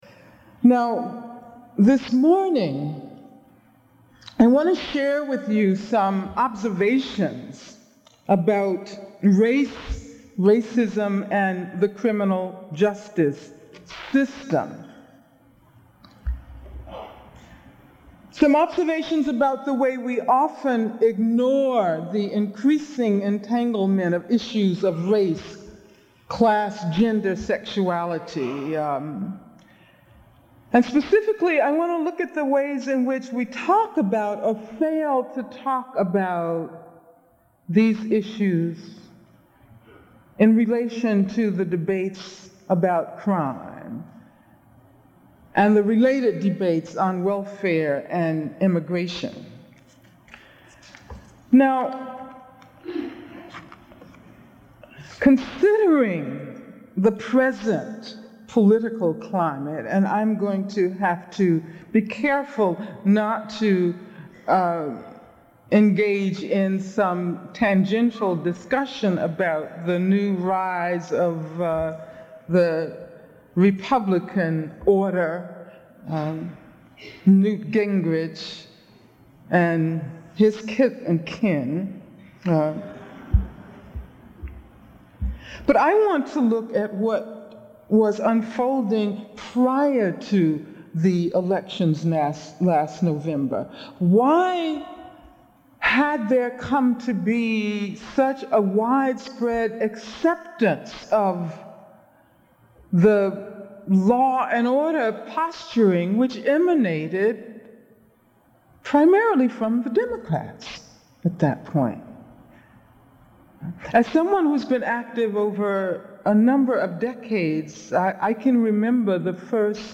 Lecture Title
Chancellor’s Fellowship Conference Keynote Address